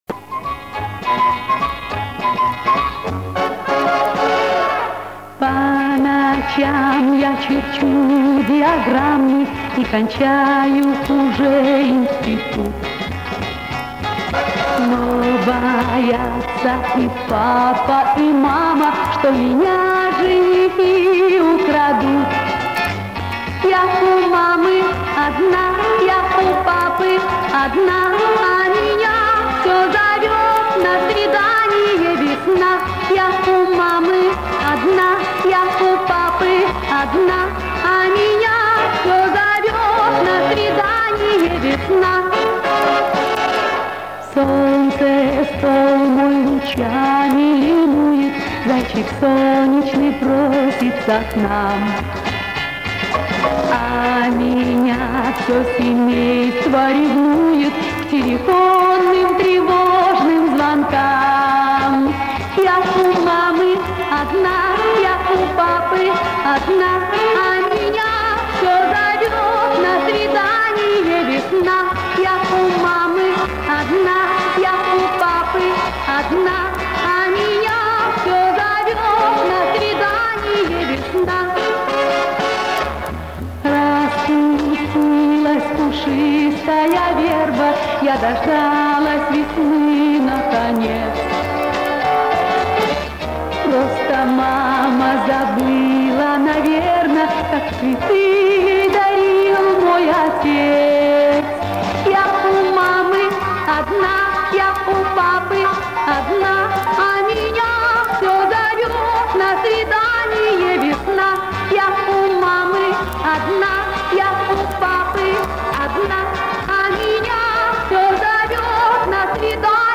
снятая, видимо, с гибкой пластинки